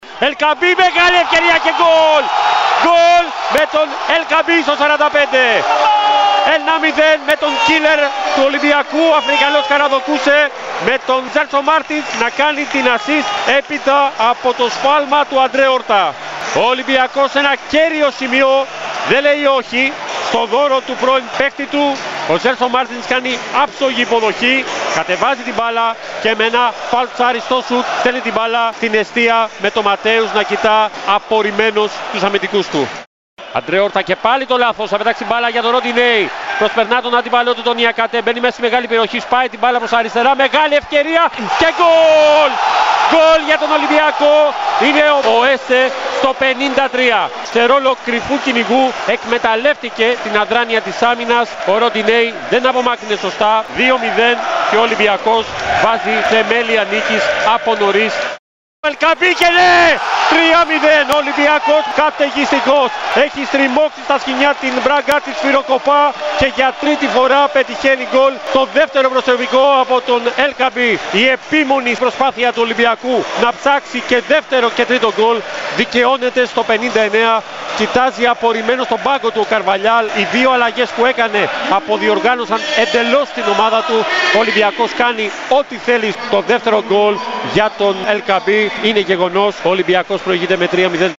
Τα γκολ του Ολυμπιακού όπως μεταδόθηκαν από τη συχνότητα της ΕΡΑ ΣΠΟΡ: